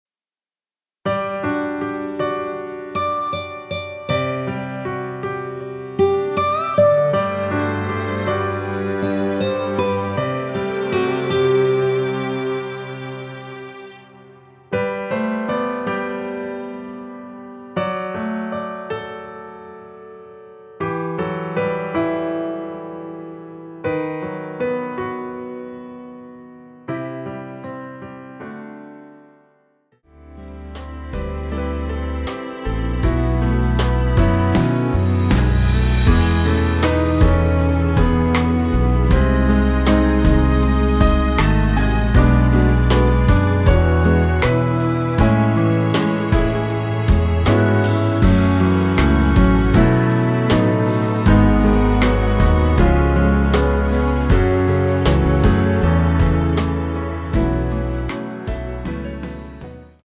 앞부분30초, 뒷부분30초씩 편집해서 올려 드리고 있습니다.
중간에 음이 끈어지고 다시 나오는 이유는
곡명 옆 (-1)은 반음 내림, (+1)은 반음 올림 입니다.